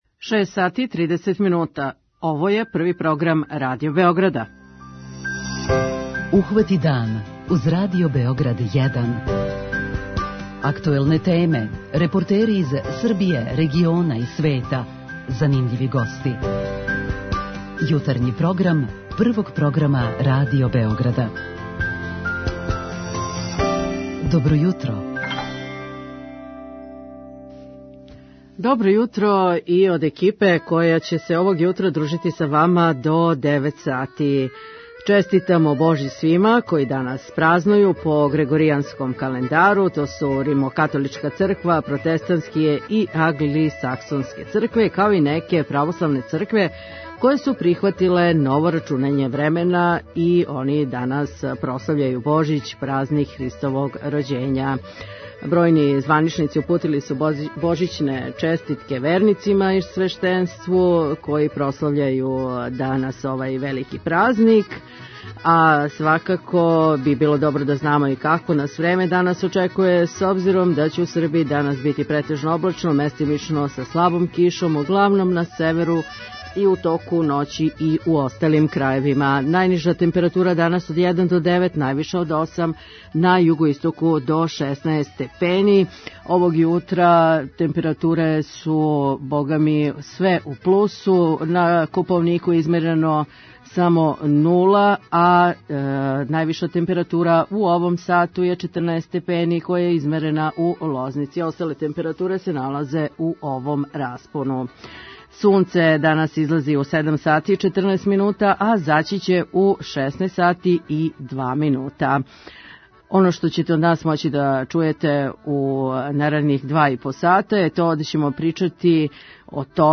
преузми : 27.04 MB Ухвати дан Autor: Група аутора Јутарњи програм Радио Београда 1!